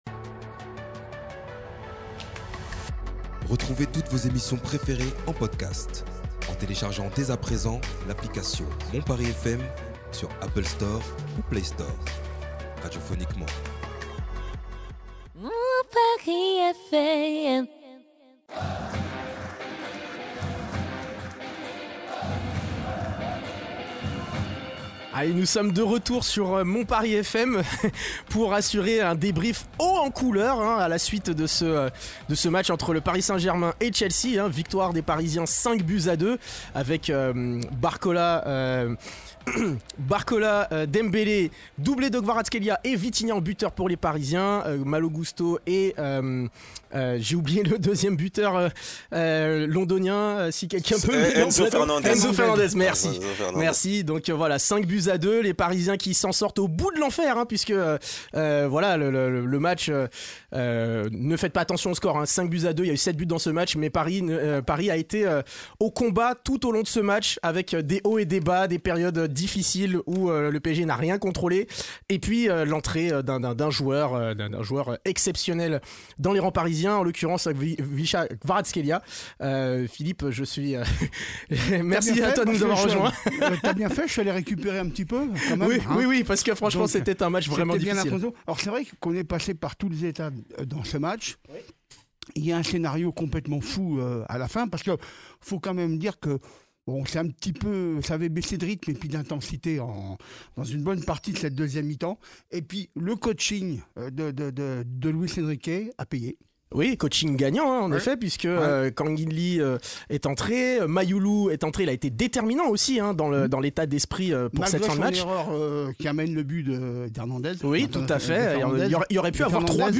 Revivez le debrief d’après match de ce PSG-Chelsea, nos chroniqueurs décryptent la victoire 5-2 des Parisiens et se projettent sur le match retour du 17 mars prochain à Stamford Bridge.